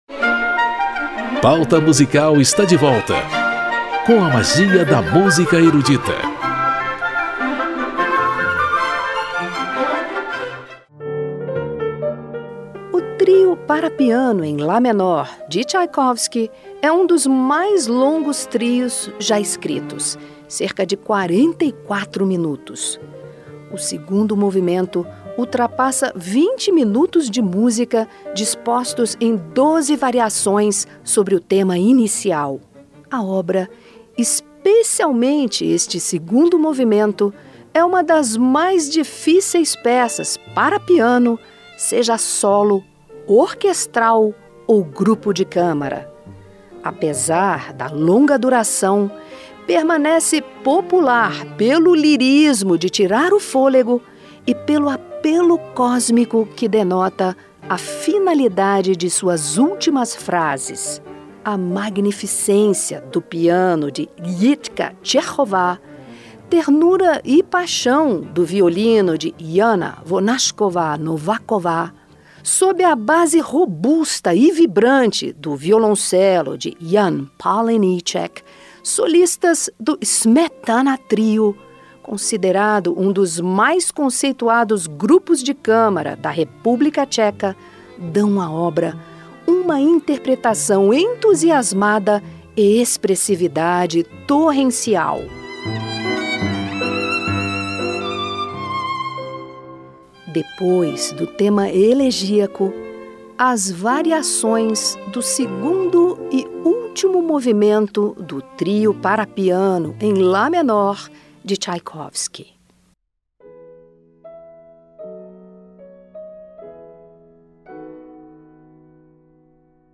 Uma surpreendente história de superação, que culminou num monumento levantado pelo compositor em memória do amigo morto. Pianista
violinista
violoncelista